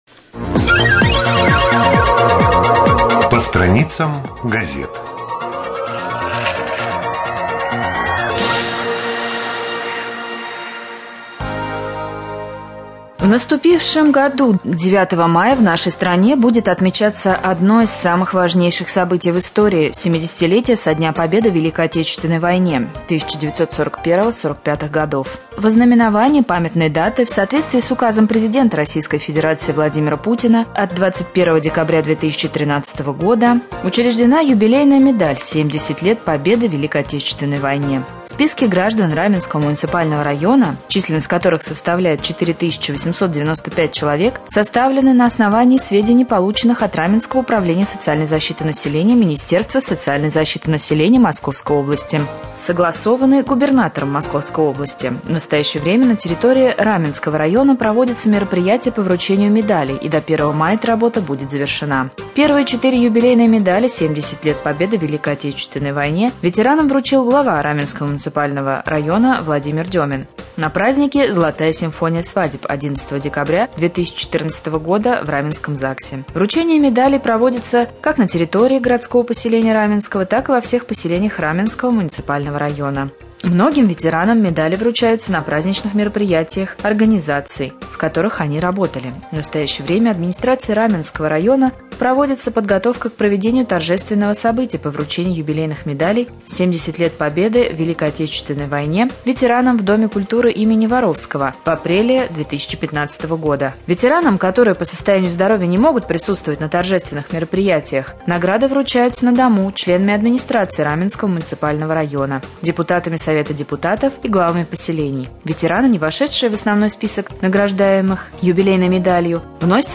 26.03.2015 г. в эфире Раменского радио